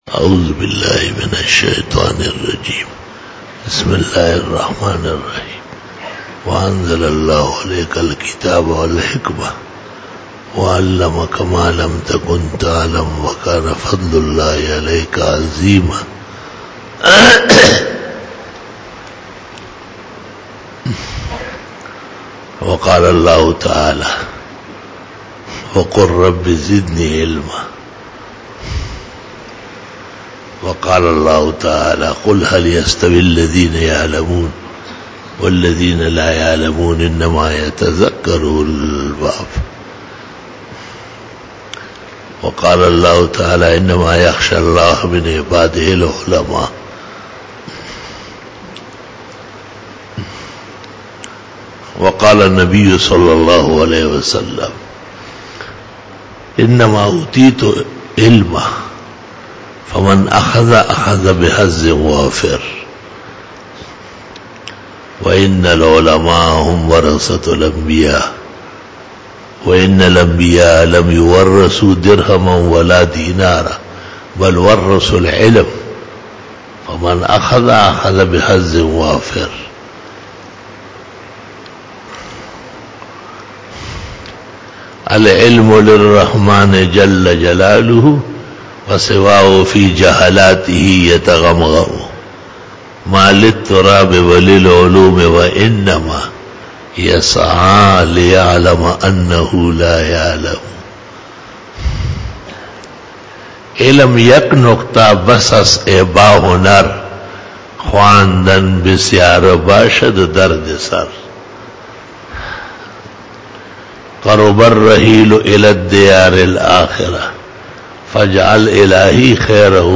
11 BAYAN E JUMA TUL MUBARAK (15 March 2019) (07 Rajab 1440H)